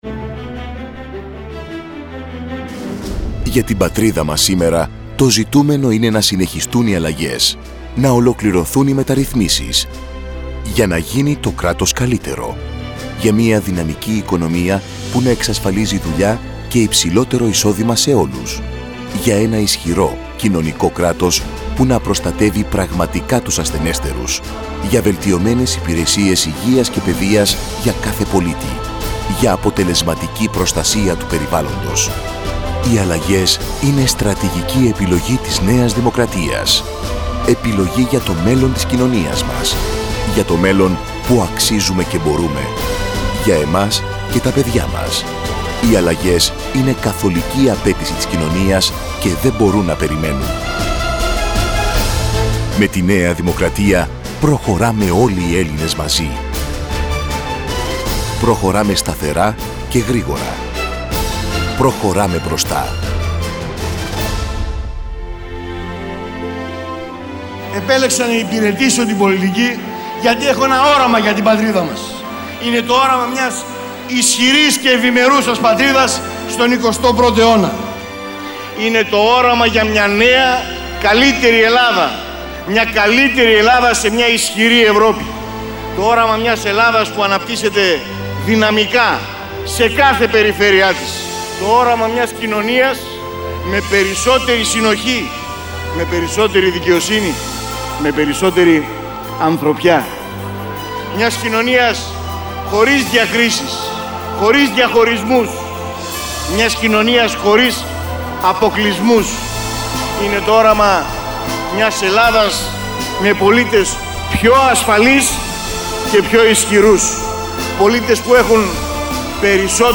Ραδιοφωνικό Spot ΝΕΑ ΔΗΜΟΚΡΑΤΙΑ Εκλογές 2007 mp3 file 9,1 Mb
radio spot nd.mp3